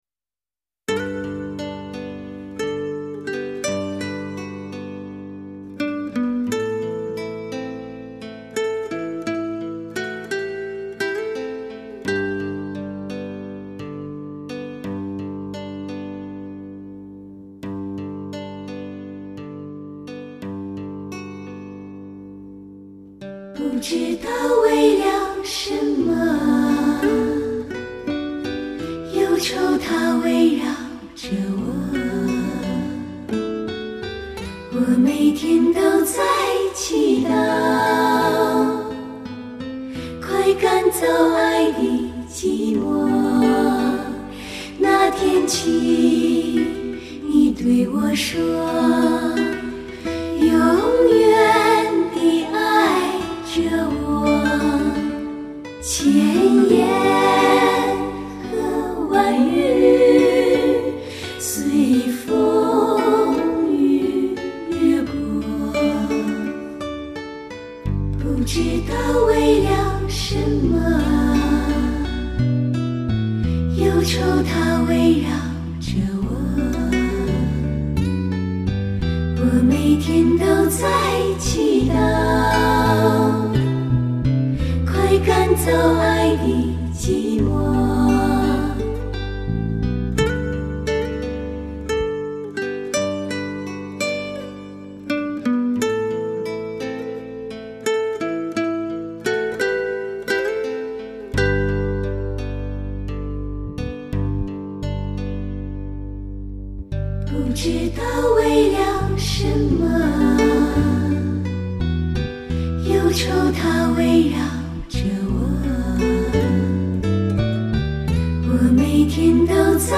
亚洲最热女子合唱组
用最新的DSD录音技术灌制而成